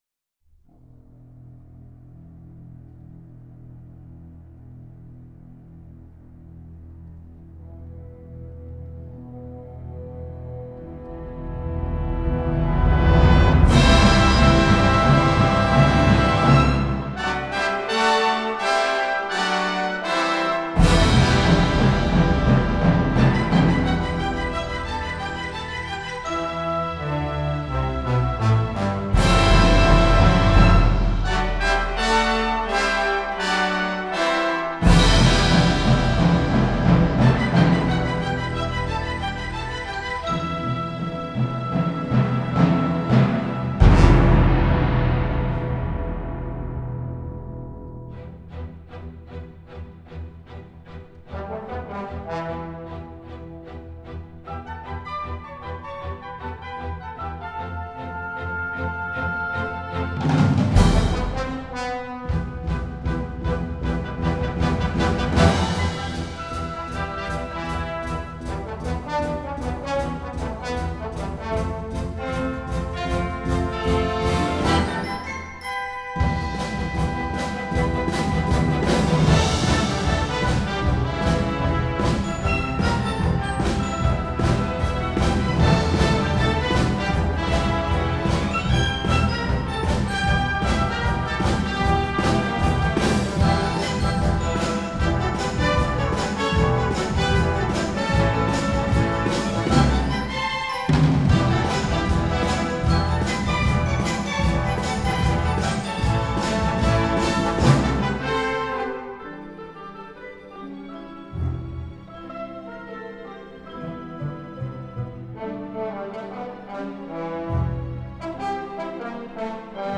Orchestral Version